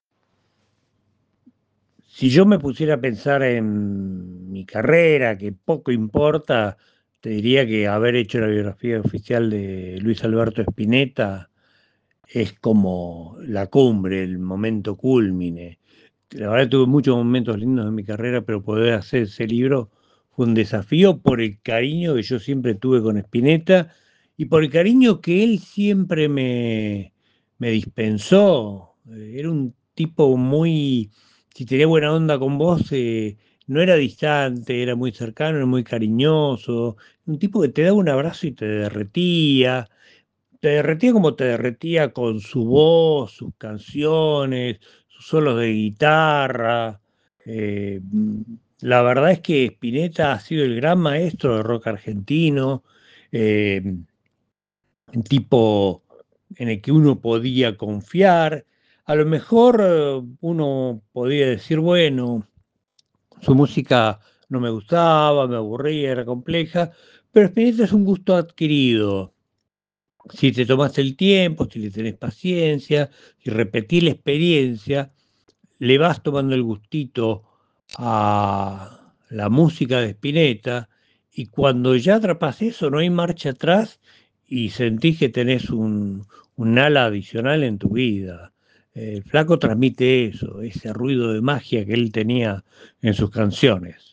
en diálogo con Diario Confluencia